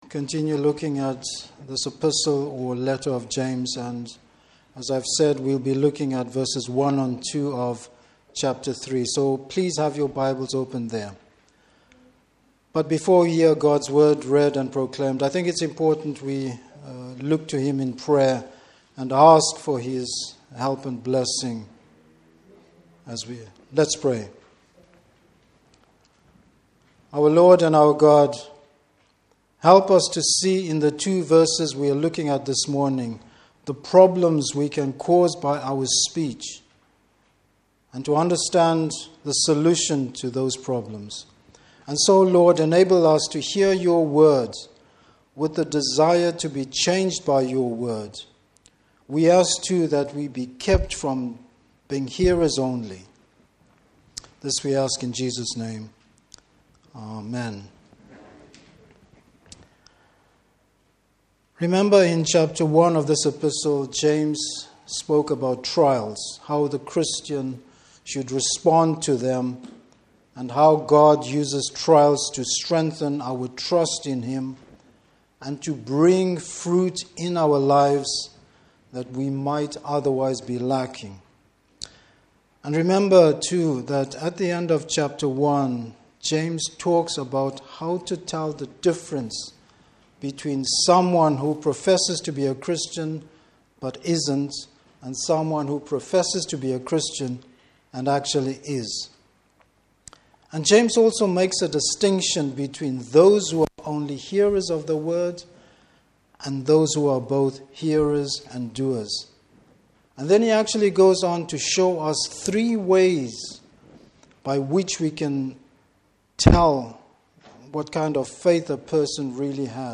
Service Type: Morning Service The authority of God’s Word and the responsibility of teaching it.